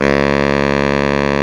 Index of /90_sSampleCDs/Roland L-CDX-03 Disk 1/SAX_Sax Ensemble/SAX_Solo Sax Ens
SAX B.SAX 0S.wav